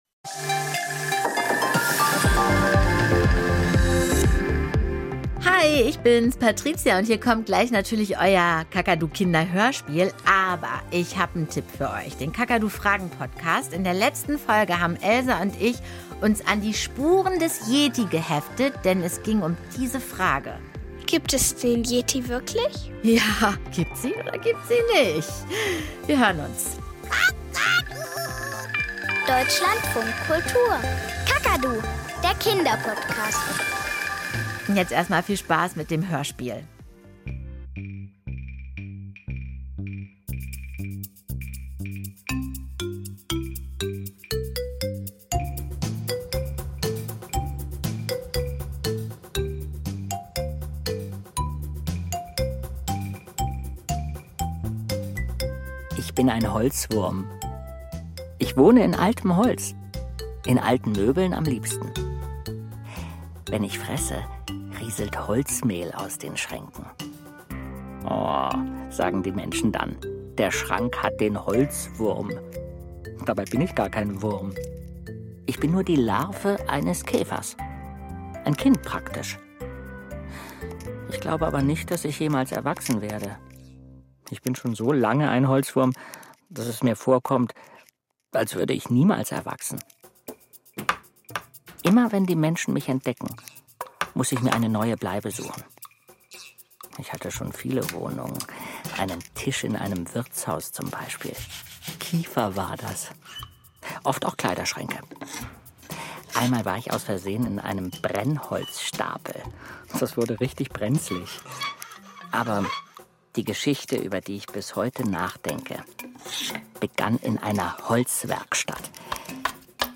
Kinderhörspiel - Pinocchio